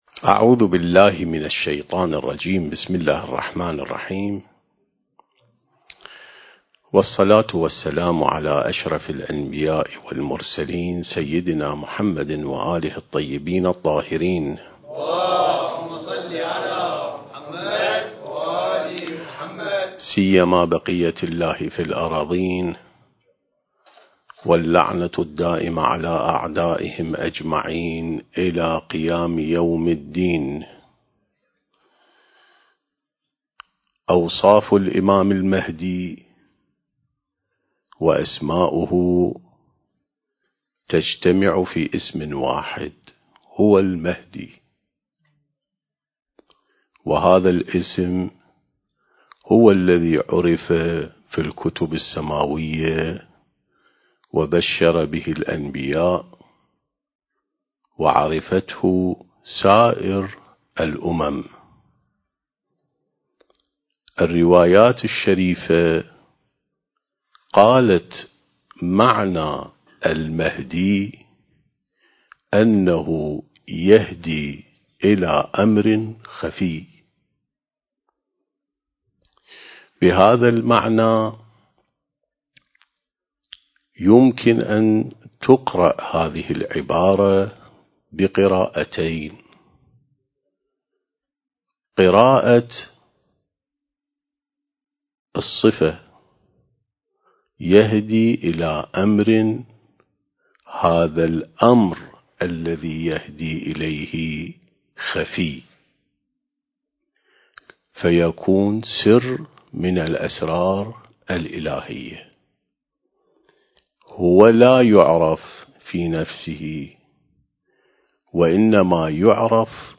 سلسلة محاضرات عين السماء ونهج الأنبياء (3) التاريخ: 1443 للهجرة